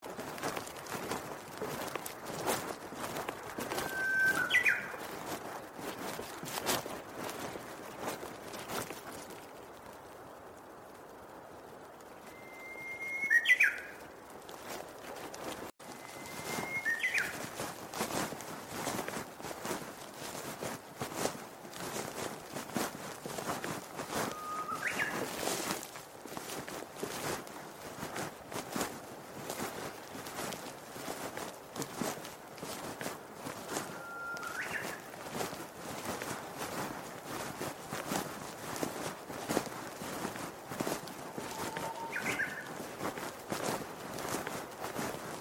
ウグイスとコマドリの撮影と囀りの録音が叶い、予想外の収穫となりました。
ウグイスの囀り